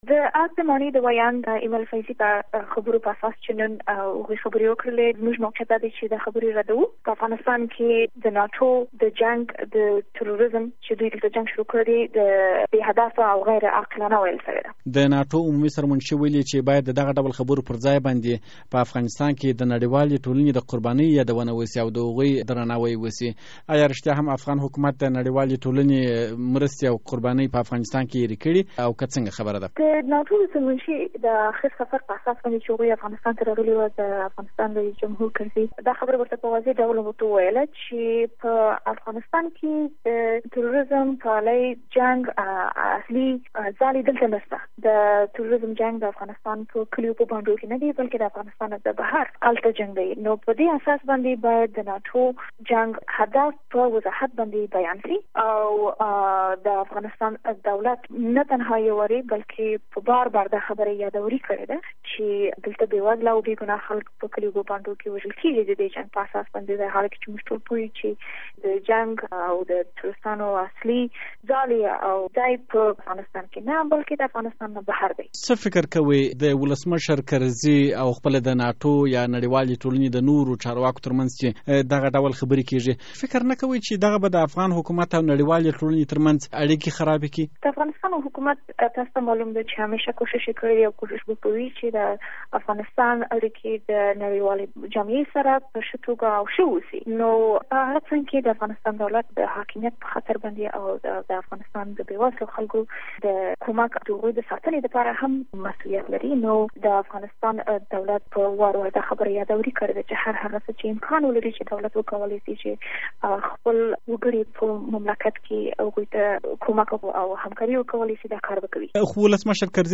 له عادلې راز سره مرکه